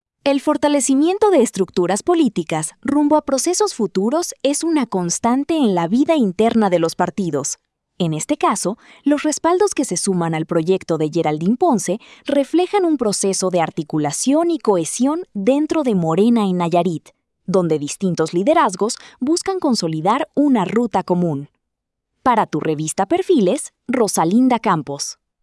COMENTARIO EDITORIAL 🎙